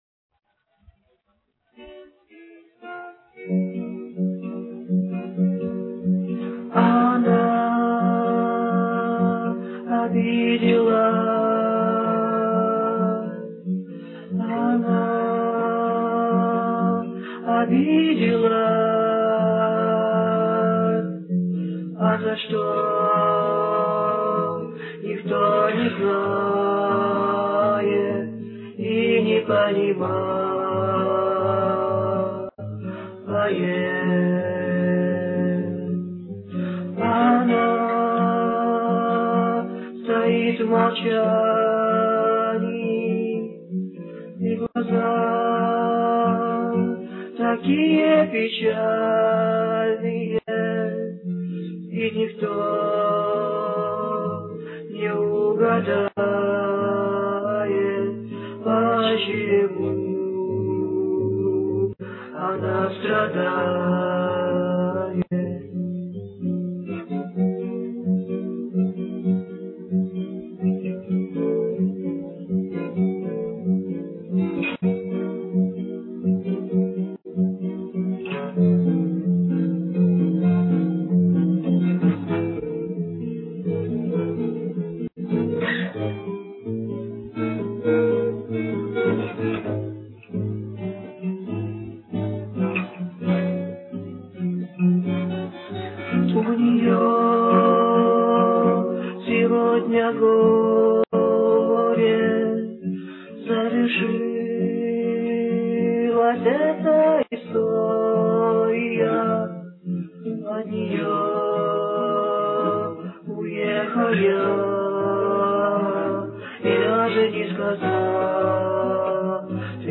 Bosa-nova